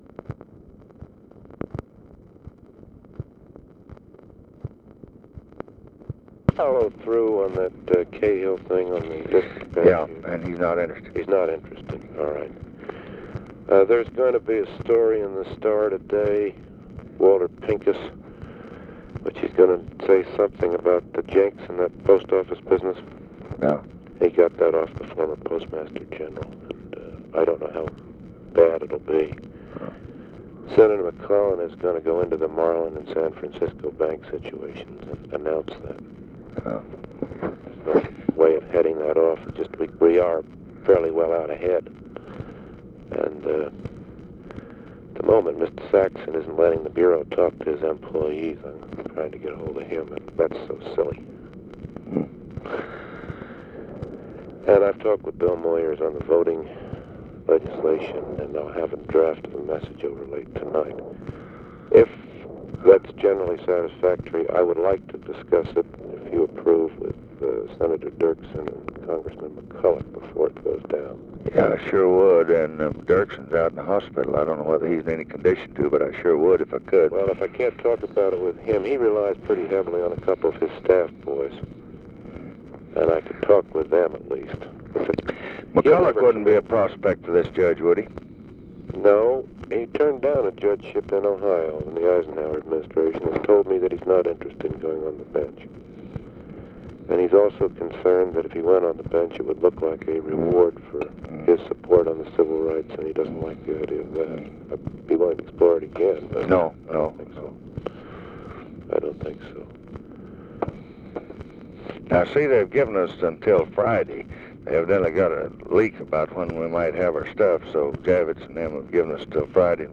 Conversation with NICHOLAS KATZENBACH, February 24, 1965
Secret White House Tapes